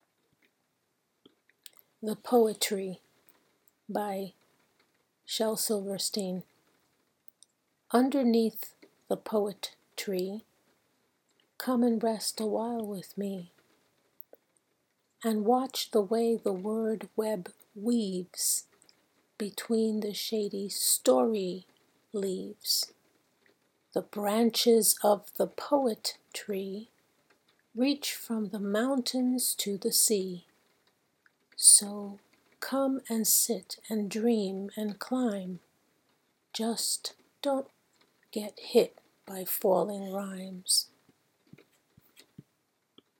Here is my voice recording of The Poet Tree by Shel Silverstein.